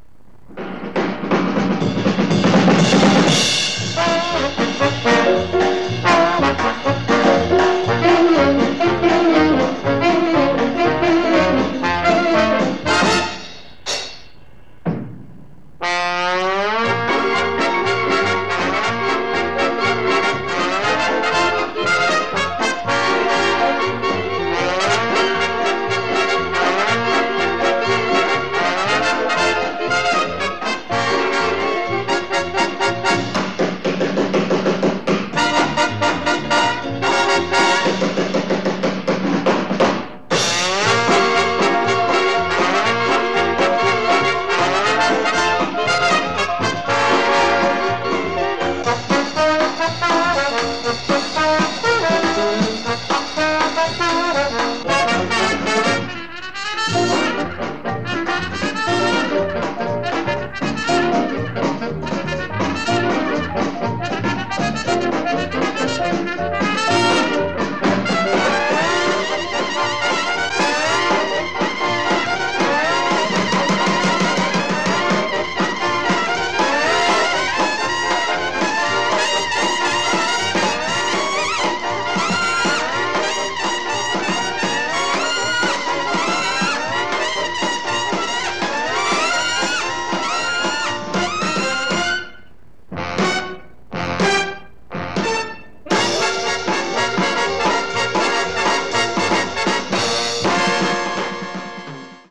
le musiche jazziste
Track Music